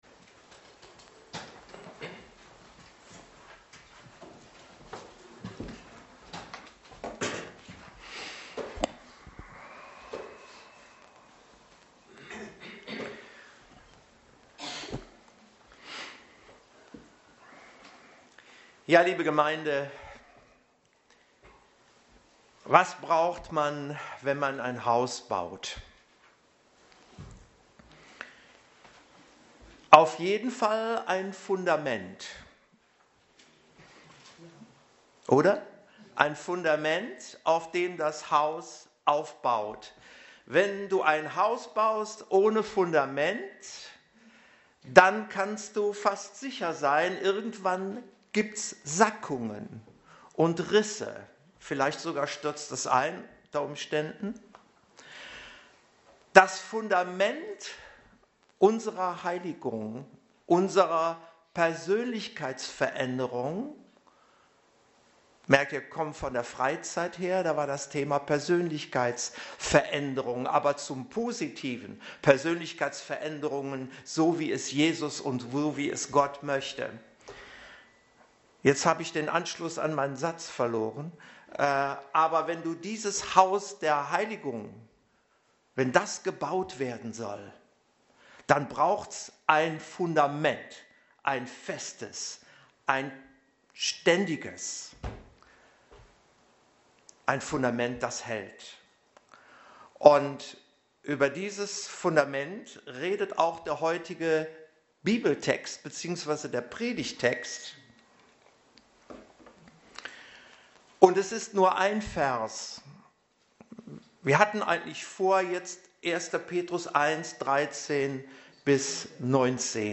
Serie: Heiligkeit und Heiligung Passage: 1. Petrus 1,13-19 Dienstart: Predigt Themen: Fundament , Heiligung , Verwandlung « Die Freude der Buße Bereit!